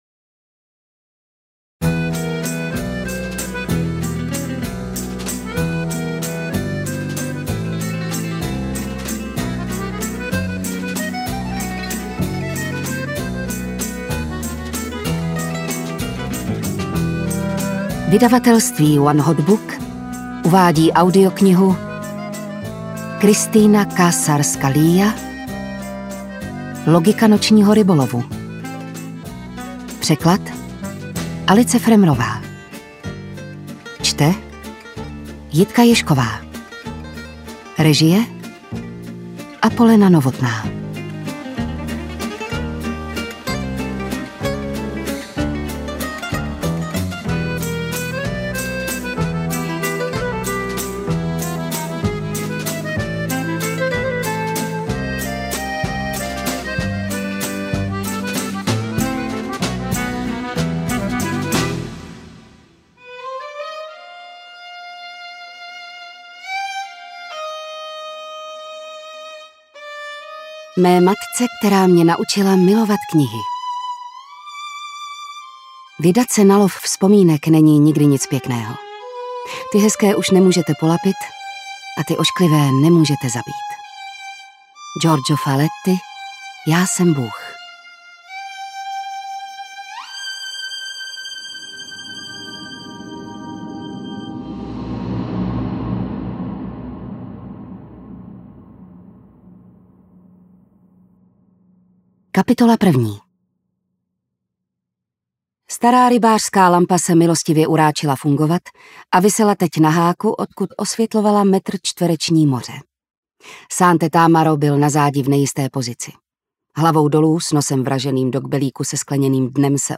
AudioKniha ke stažení, 33 x mp3, délka 10 hod. 50 min., velikost 596,8 MB, česky